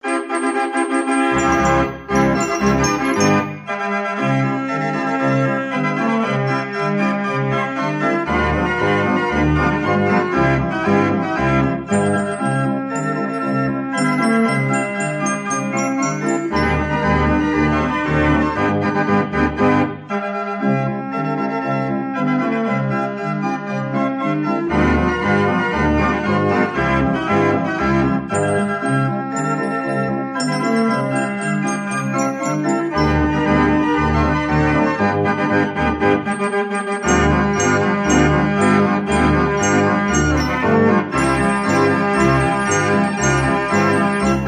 BAND ORGANS/FAIR ORGANS